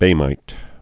(bāmīt, bō-)